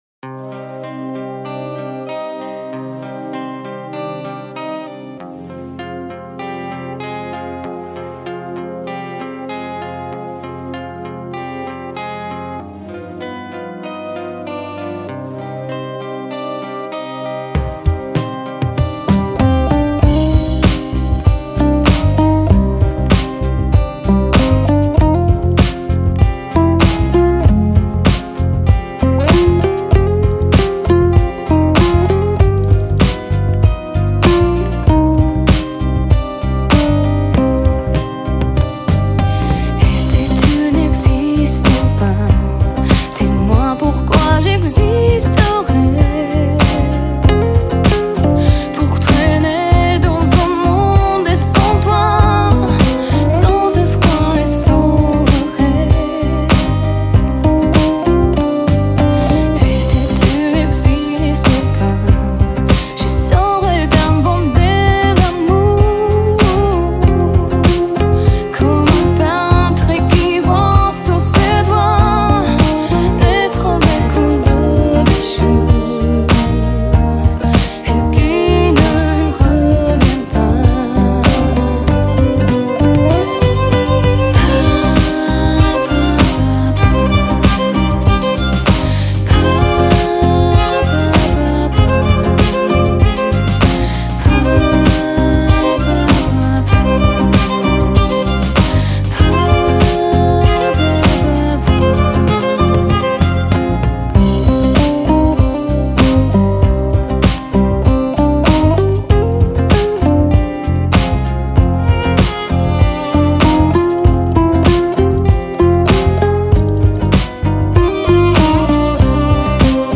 сочетающее элементы поп и шансон.